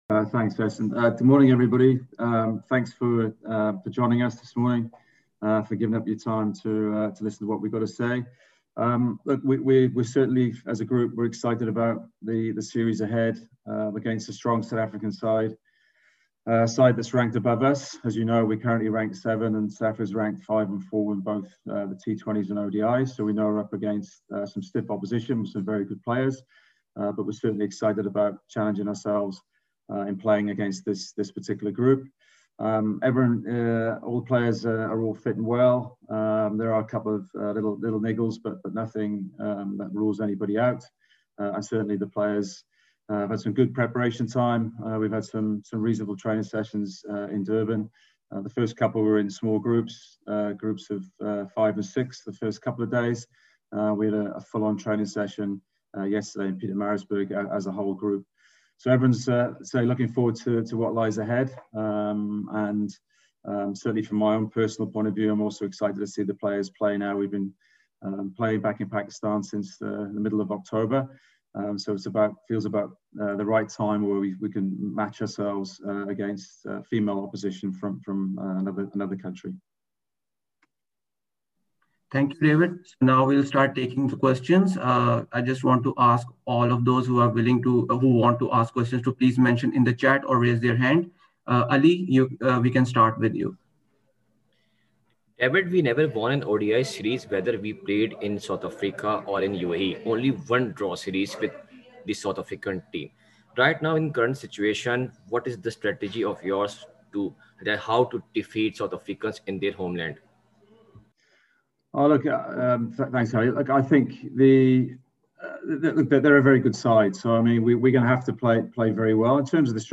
held a virtual media conference today.